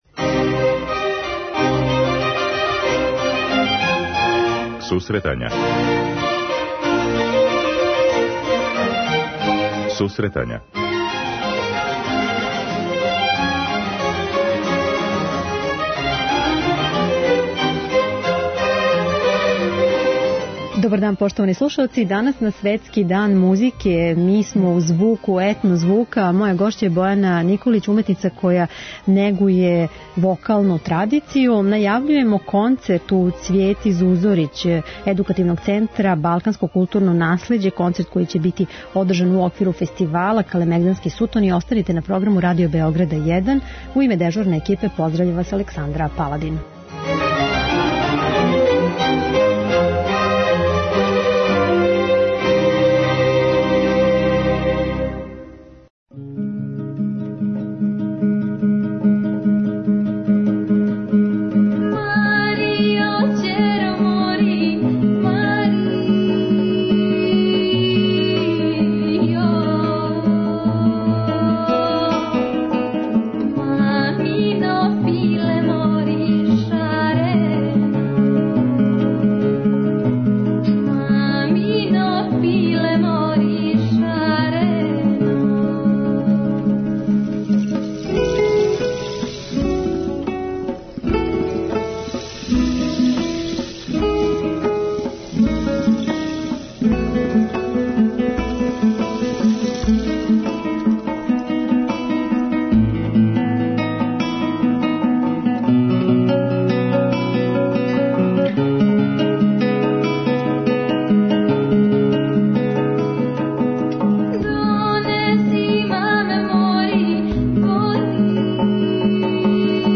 Гошћа